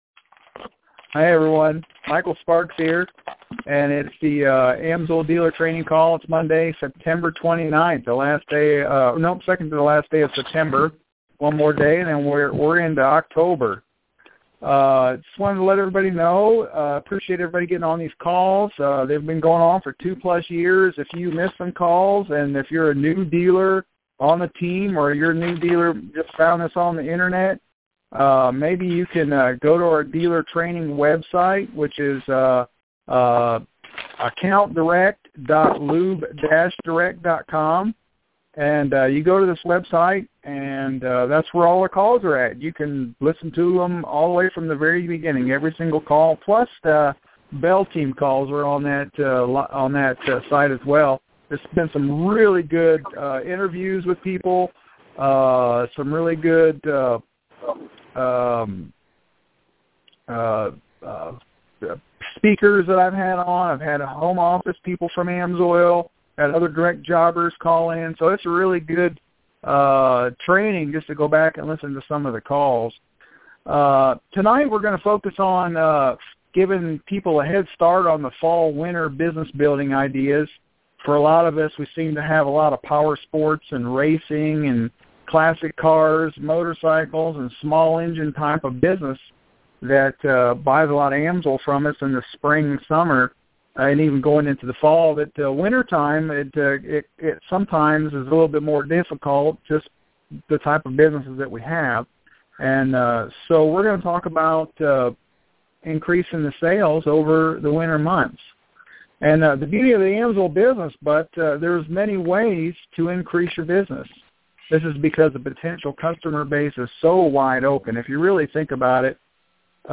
Sparks Team AMSOIL Dealer Training Call | September 29th, 2014